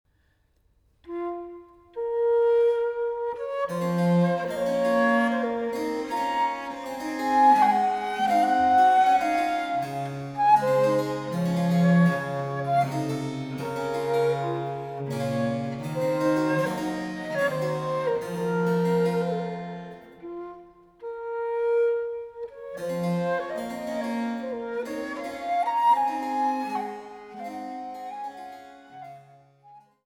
Gayment